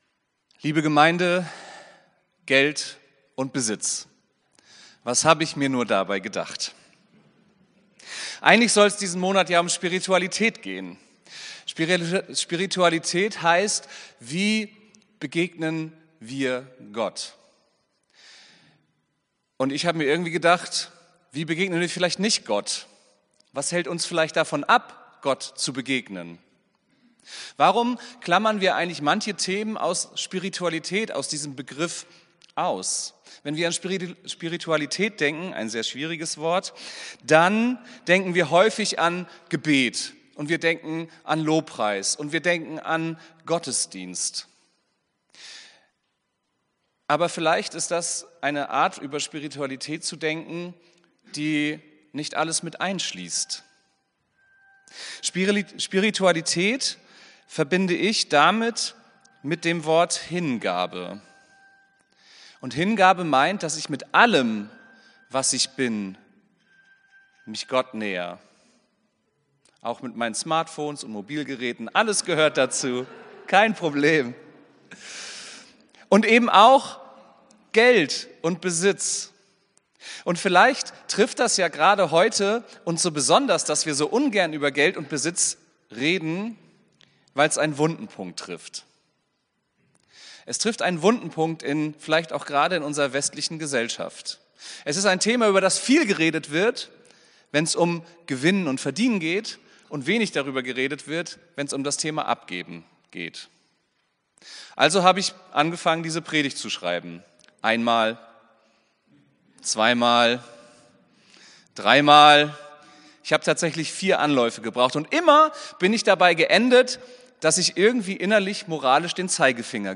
Predigt vom 09.02.2025